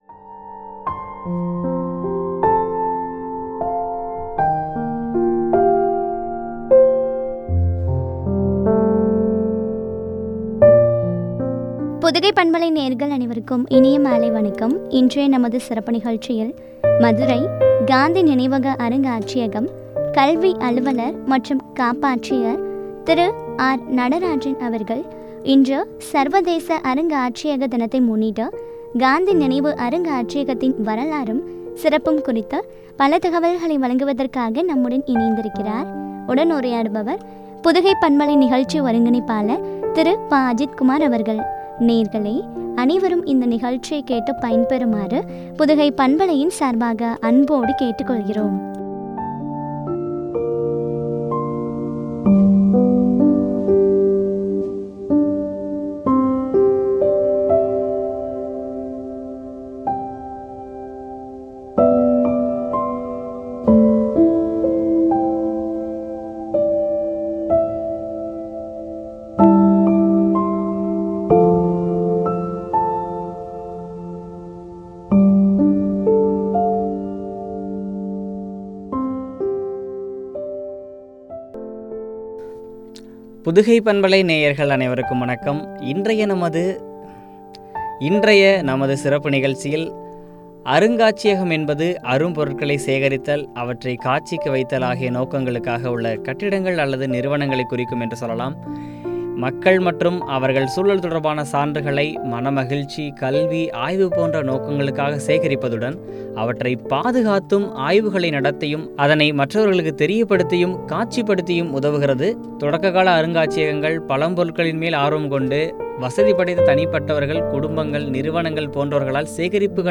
சிறப்பும் பற்றிய உரையாடல்.